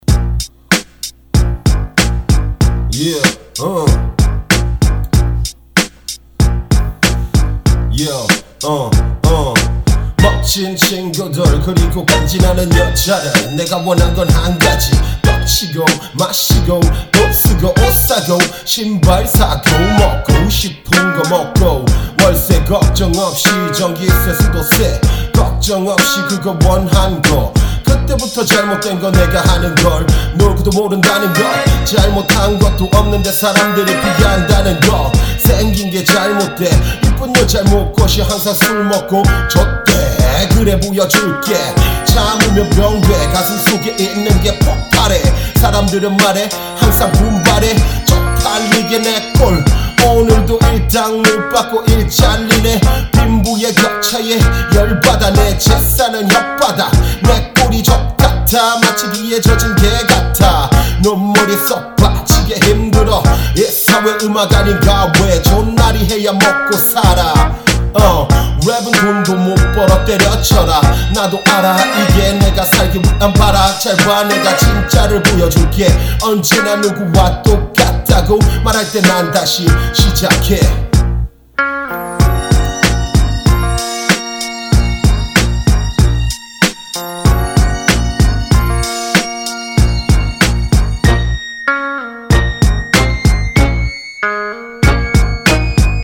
• [국내 / REMIX.]